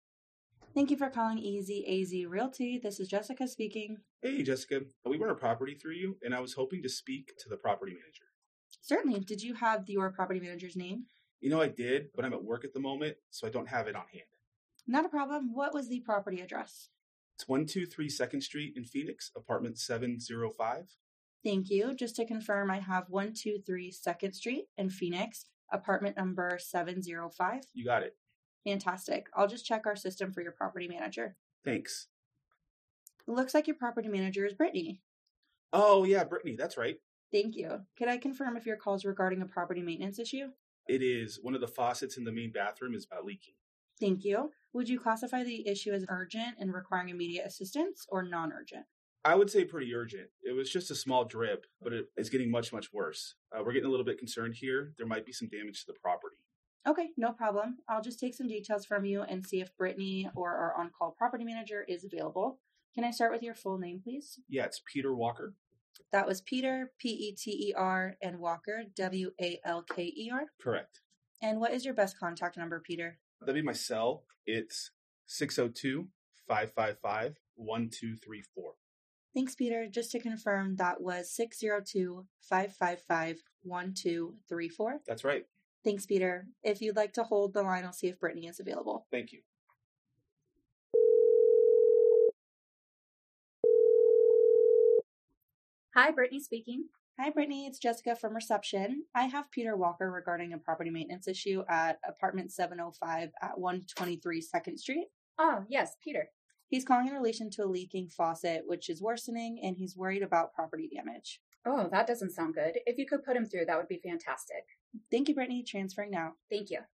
real-estate-answering-service-call-sample-RHQ.mp3